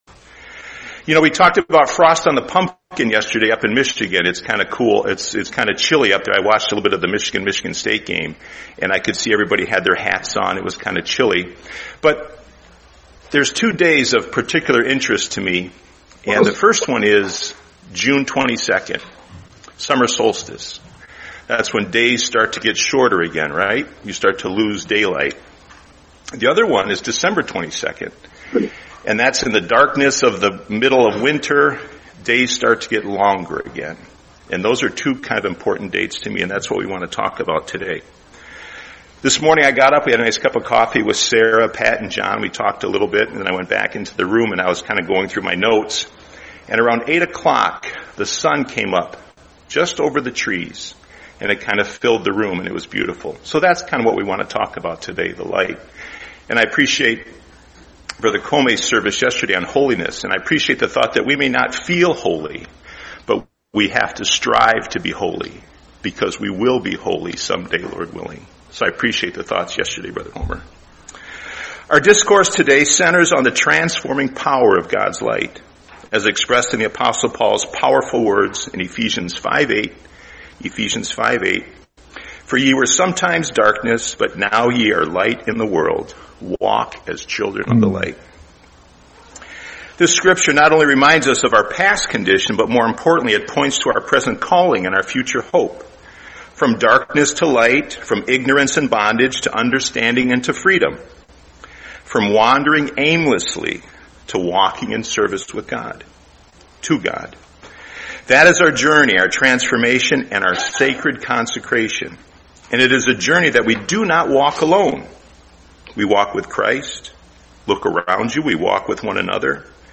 Series: 2025 Orlando Convention